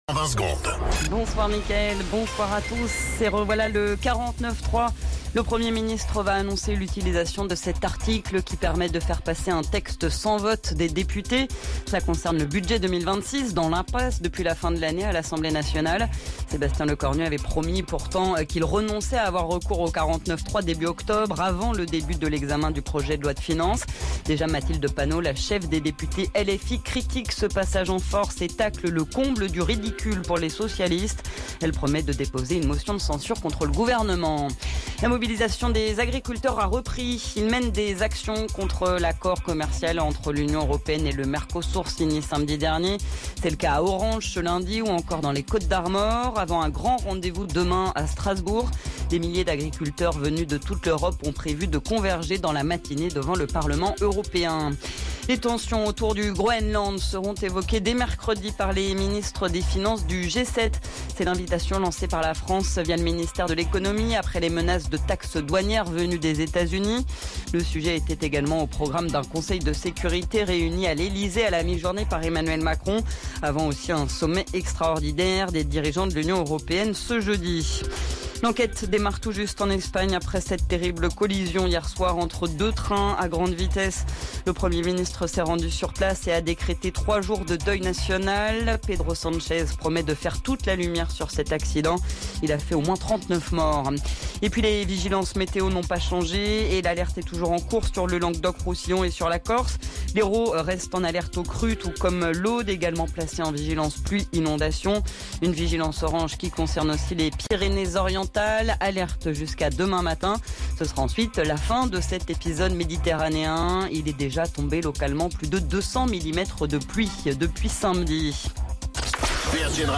Flash Info National 19 Janvier 2026 Du 19/01/2026 à 17h10 .